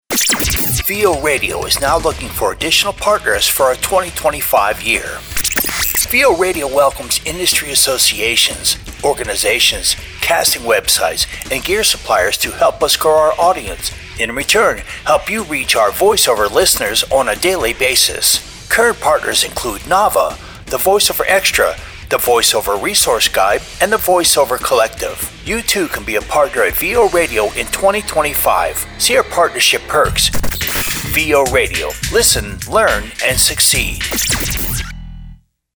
Sample Commercial